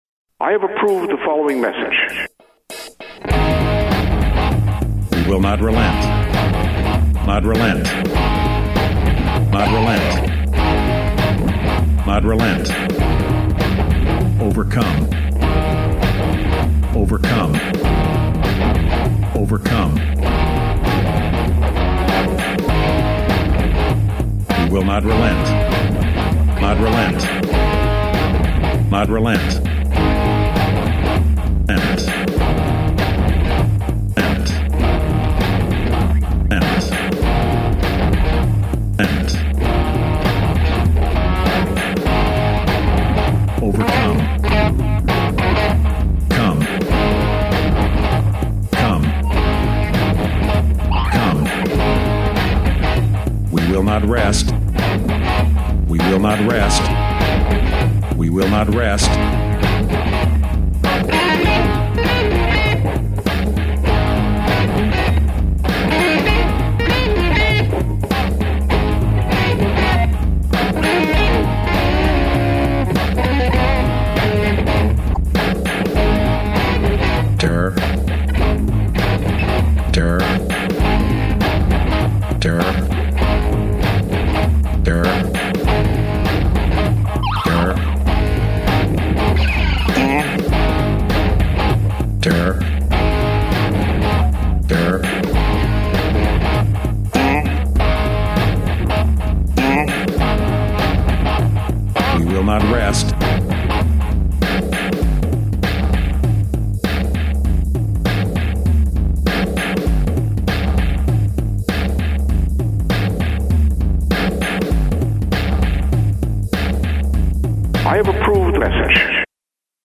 rock operetta
Fourteen cuts of war fever classic rock